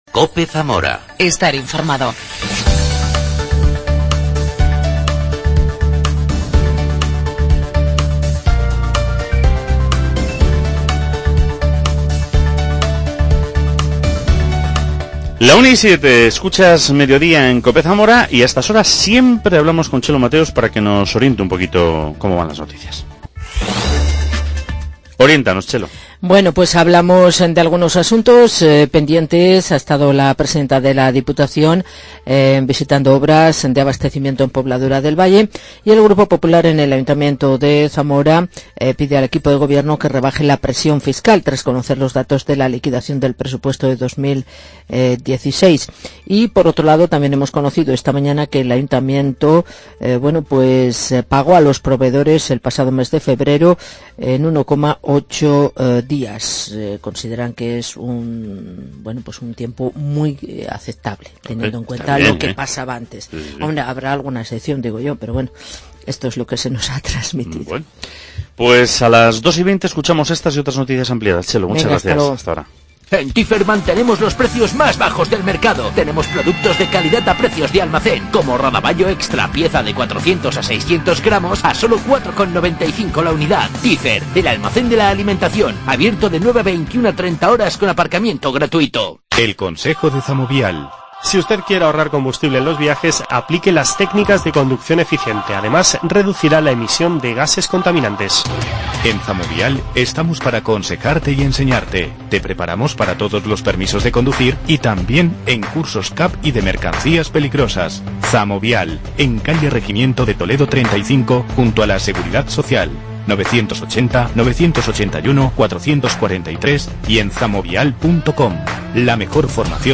habla con la actriz María Adánez, protagonista de "Lulú", que representa mañana en el Teatro Principal de Zamora.